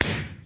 firework2.mp3